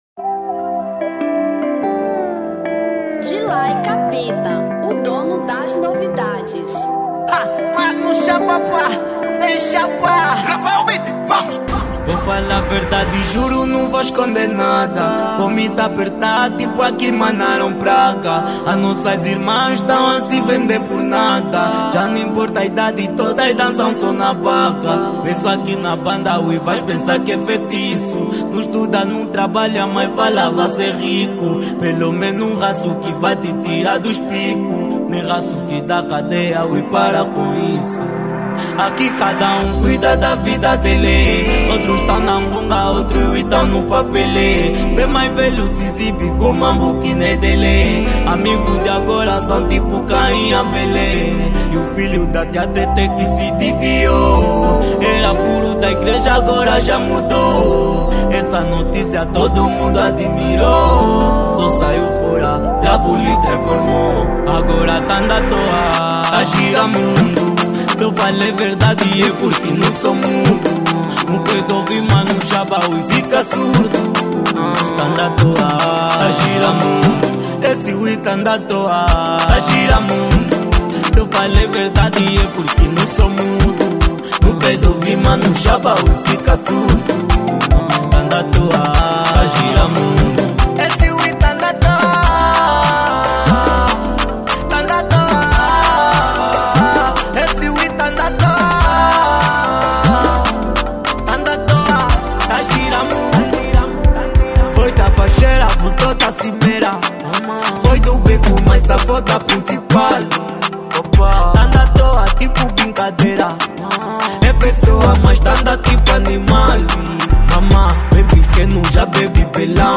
Kuduro 2024